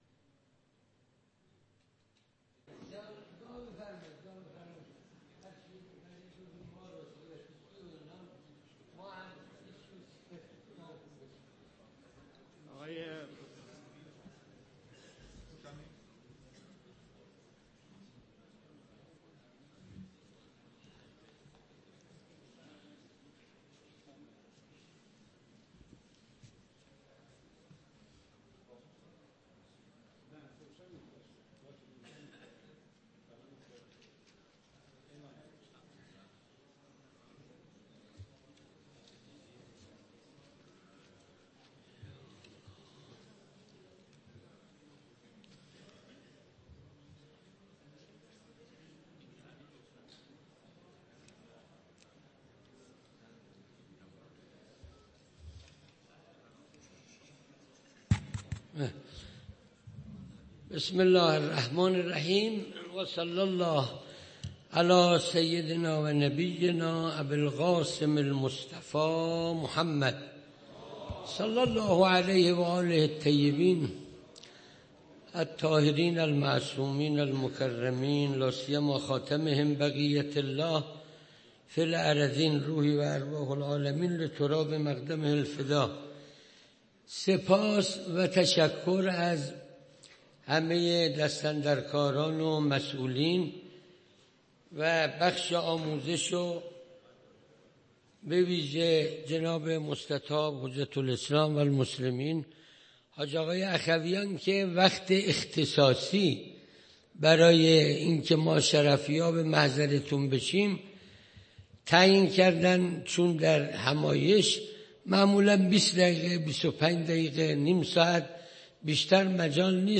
1- کارگاه آموزشی مناسک ویژه خادمان فرهنگی حج تمتع 1404